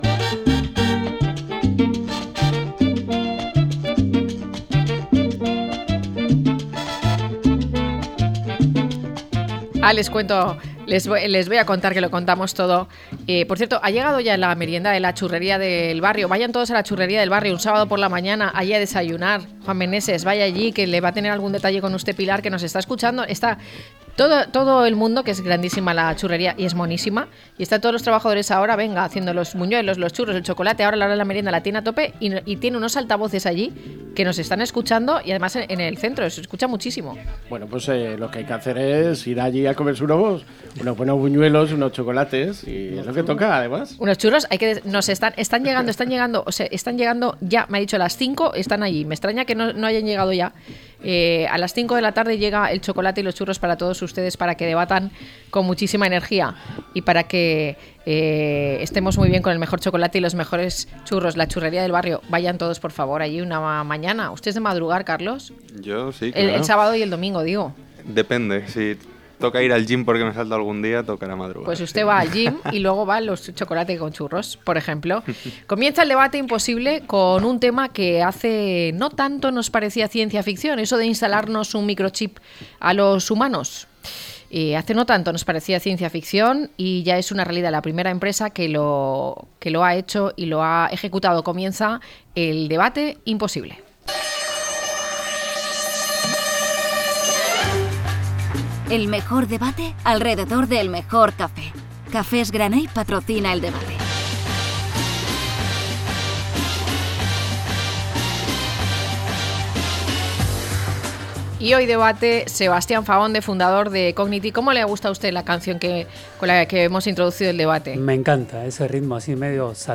0201-LTCM-DEBATE.mp3